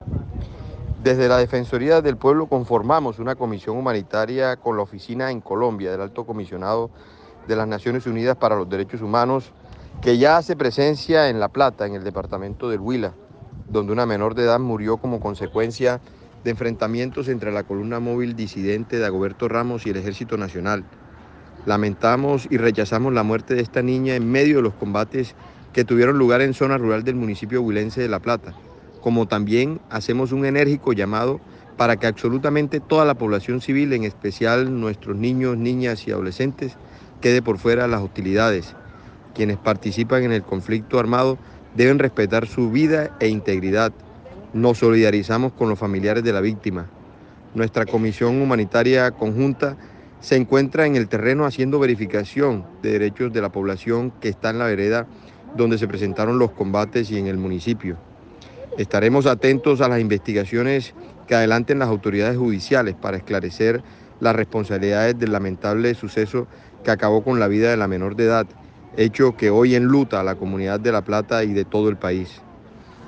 Escuche el pronunciamiento del Defensor del Pueblo, Carlos Camargo